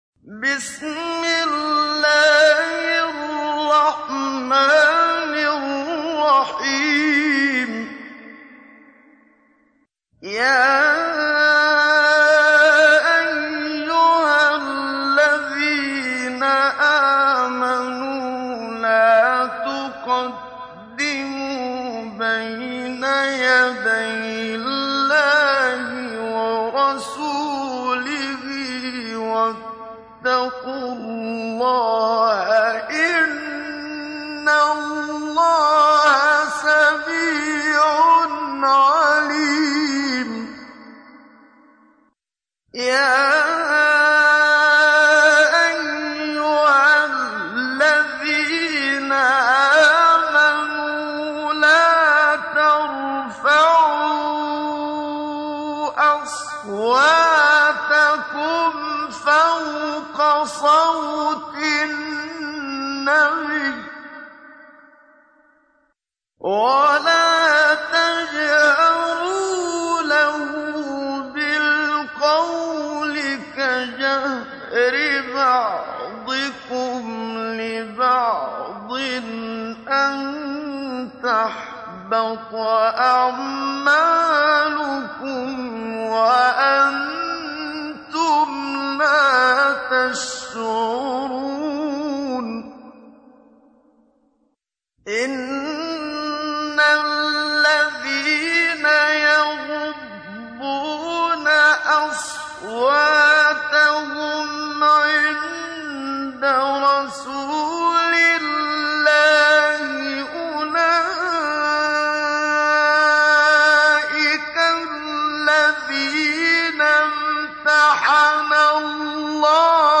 تحميل : 49. سورة الحجرات / القارئ محمد صديق المنشاوي / القرآن الكريم / موقع يا حسين